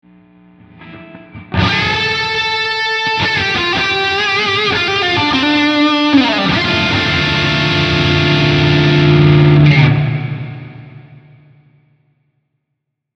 Пресет «Crunch» от Waves’ CLA Guitars